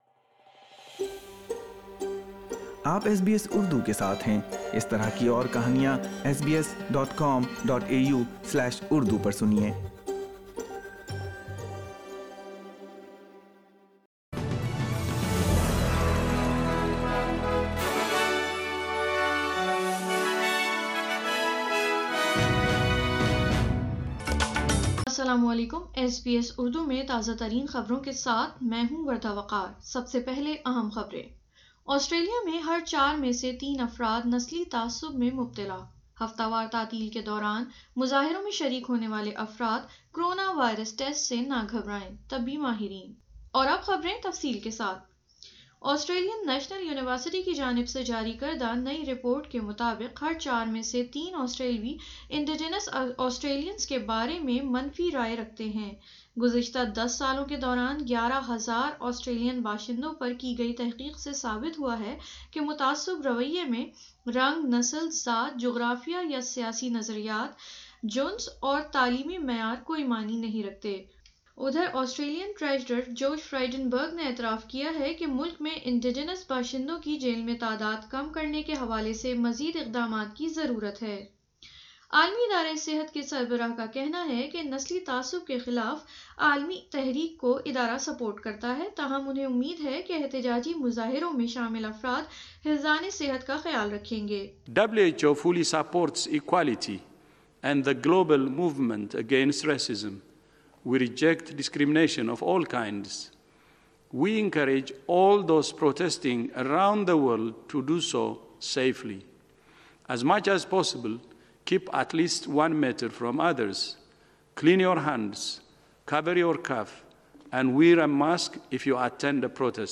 اردو خبریں 9 جون 2020